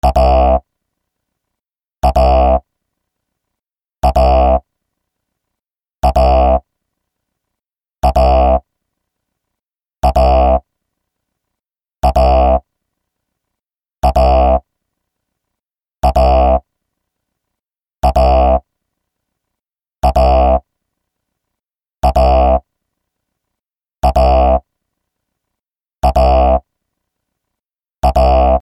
アラーム Alarm 08ブブン
/ G｜音を出すもの / G-01 機器_警告音_アラーム_電話着信